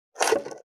480,切る,包丁,厨房,台所,野菜切る,咀嚼音,ナイフ,調理音,まな板の上,料理,
効果音